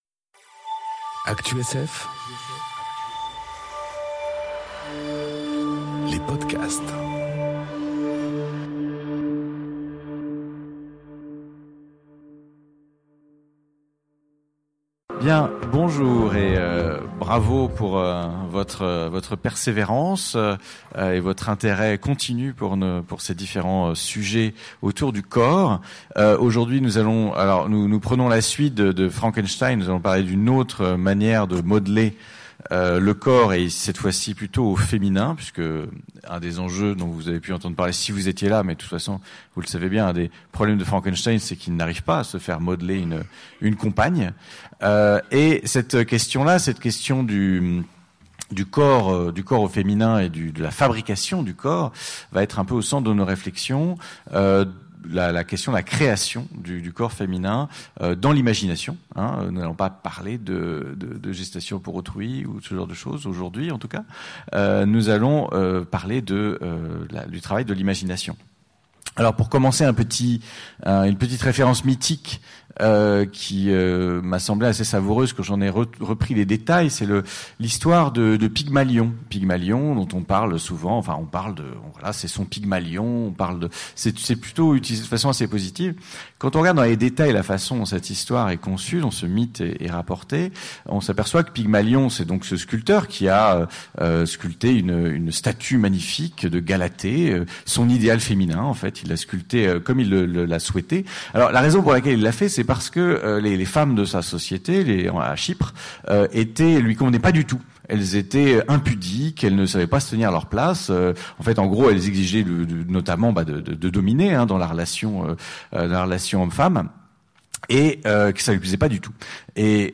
Conférence Le corps imaginaire au féminin enregistrée aux Utopiales 2018